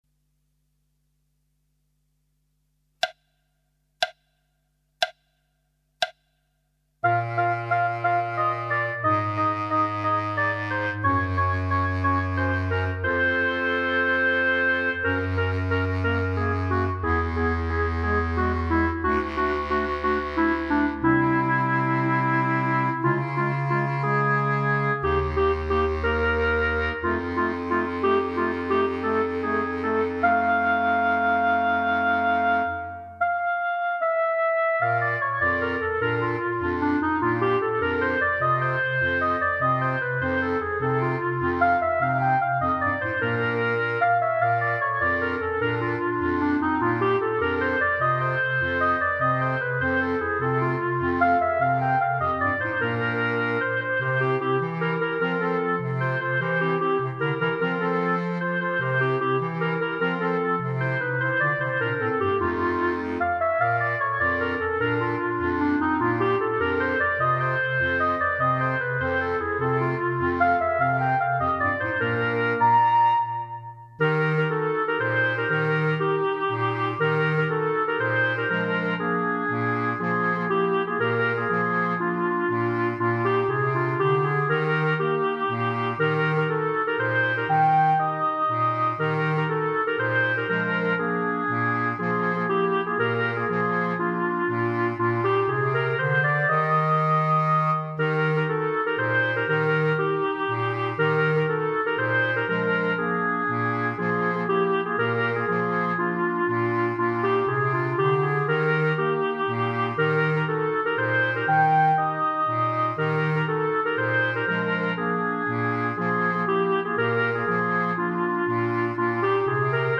minus Clarinet 4